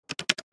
Звуки печатающего текста для YouTube влога